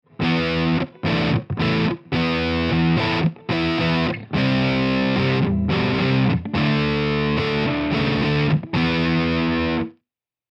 First here is a simple rock rhythm with a plexi type setting.  This is just the AU recording of the pod with a plexi setting and the 4×12 cabinets selected.
dirtyrhythmnoir1.mp3